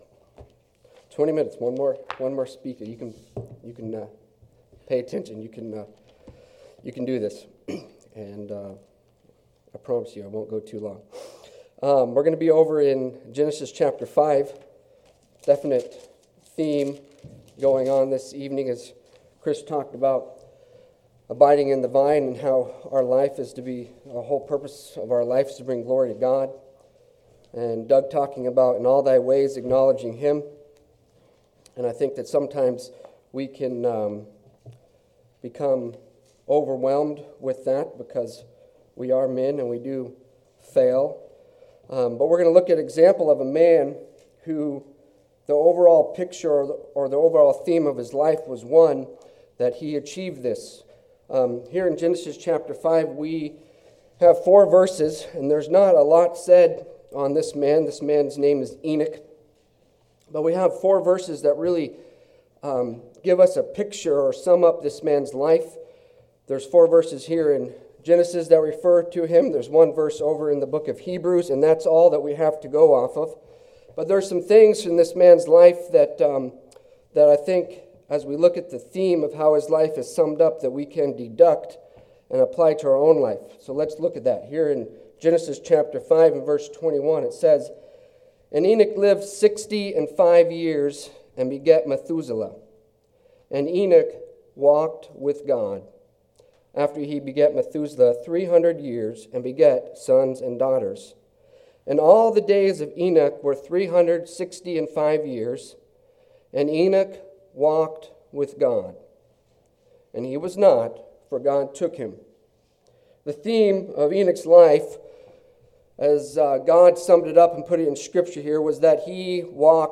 Sermons | Plack Road Baptist Church